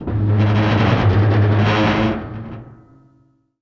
metal_scrape_deep_grind_squeak_05.wav